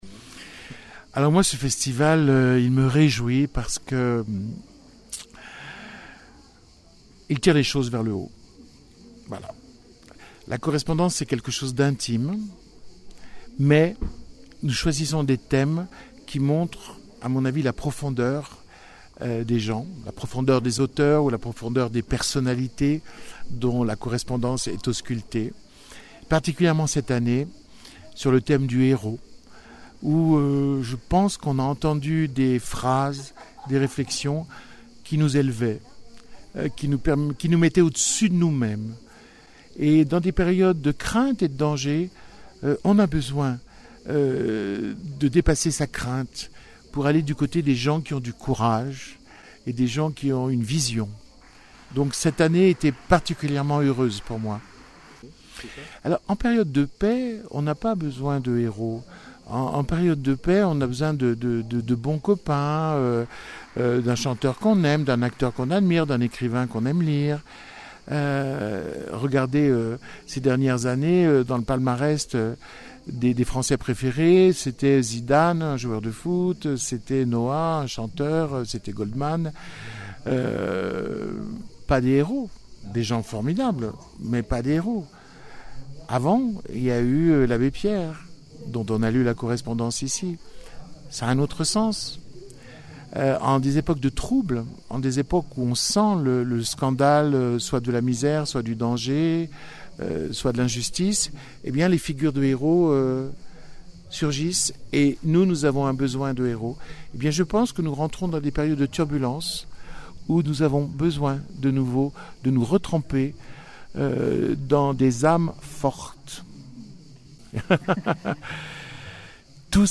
Éric-Emmanuel Schmitt, figure emblématique de la littérature contemporaine et directeur artistique du Festival de la Correspondance de Grignan, se prépare à faire un retour sur grand écran avec une adaptation de son roman "L'Évangile selon Pilate". Dans cette interview, l'auteur a partagé son enthousiasme pour ce projet cinématographique tout en soulignant les défis de collaboration avec le monde du cinéma.